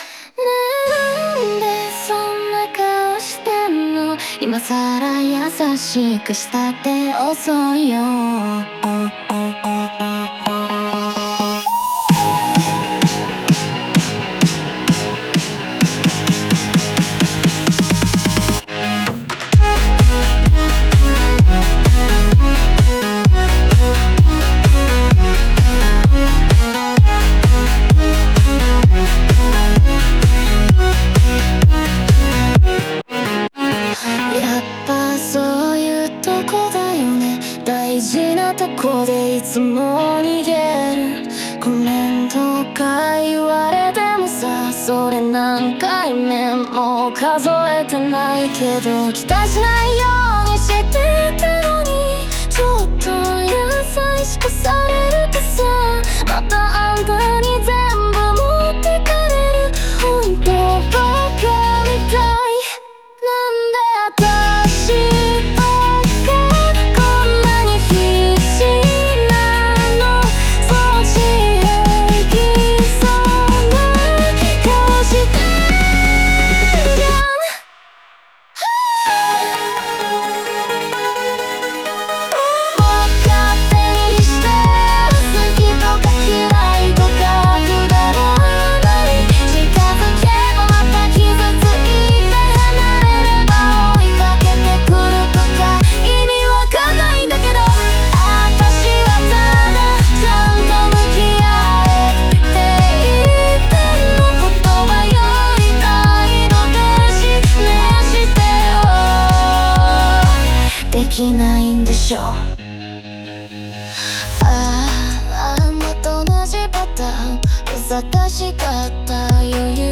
オリジナル曲♪
ビートの強さと歌詞のヒリつきがリンクし、感情がダイレクトに突き刺さる楽曲になっている。